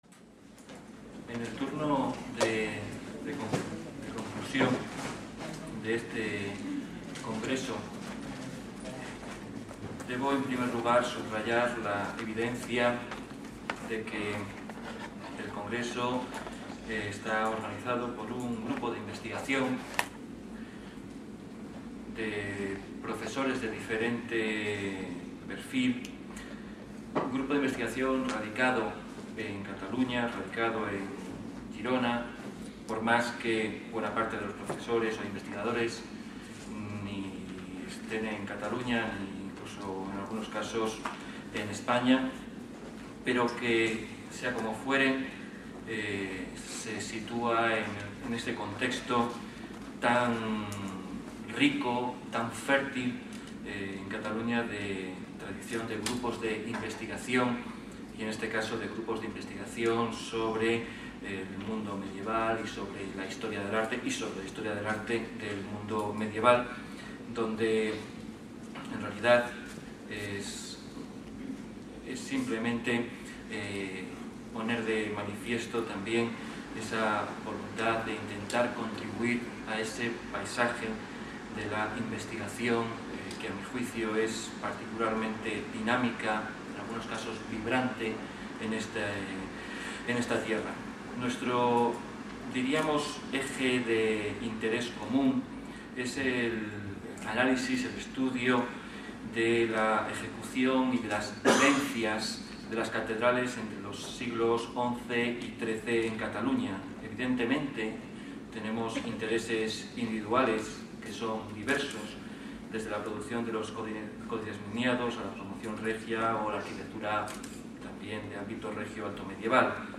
Acte de cloenda del Congrés Internacional 'Les catedrals catalanes en el context europeu (s. X-XII) : escenaris i escenografies', celebrat a Girona i a Vic els dies 7,8,9 i 10 de novembre de 2012